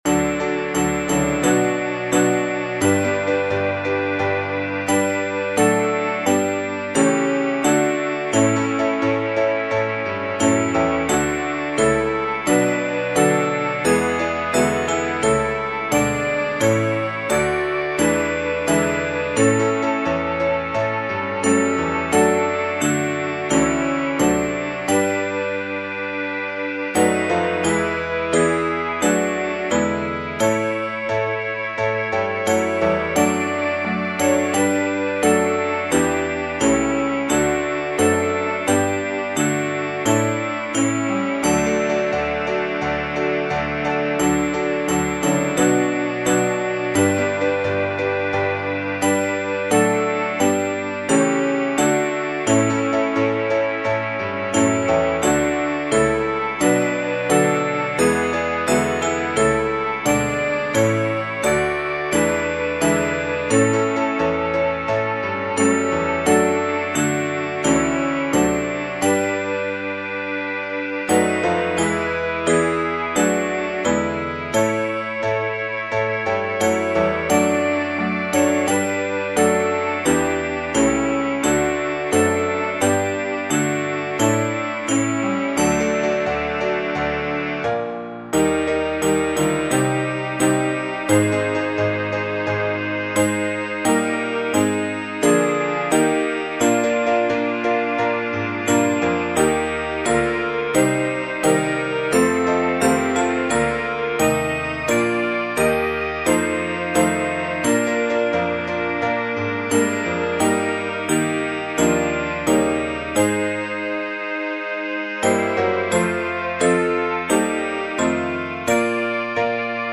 The instrument lineup is as follows: Sax section (alto, tenor, baritone) Glockenspiel Vibraphone Marimba Piano (accompaniment) Original music is public domain; text by Matthew Bridges, and music by George J. Elvey.
sacred hymn